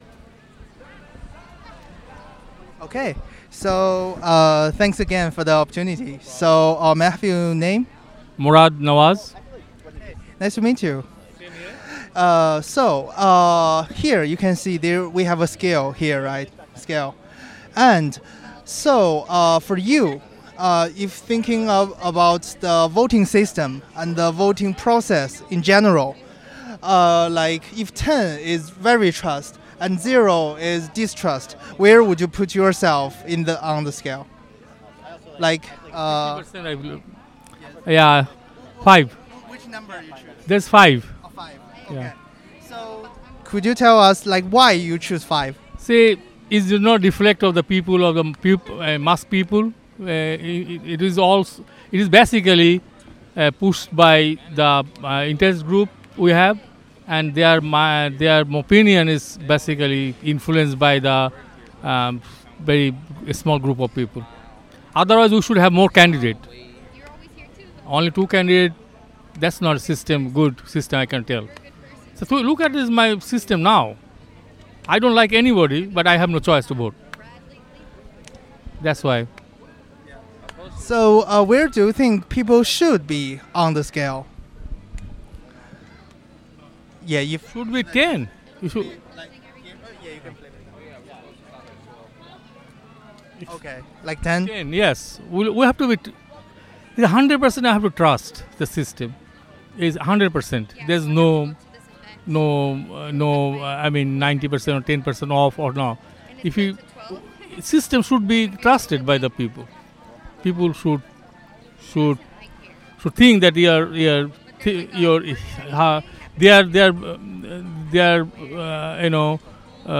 Location Despensa de la Paz